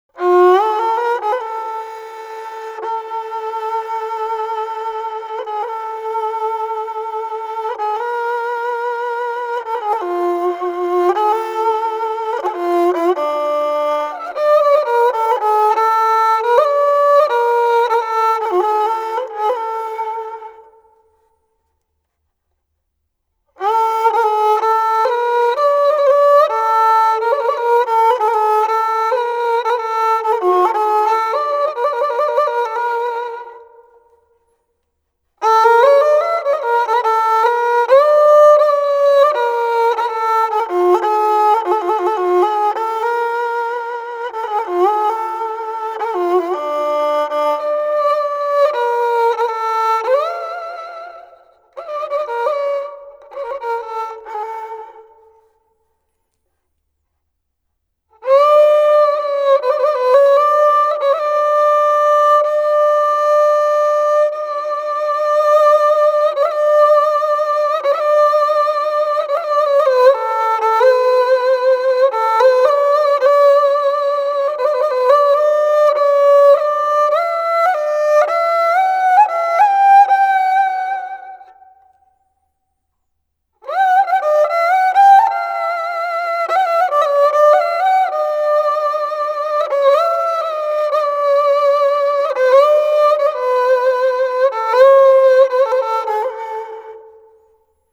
Genre: Turkish & Ottoman Classical.
Improvisation on kemence 3:31
Studio: Aria, Üsküdar, Istanbul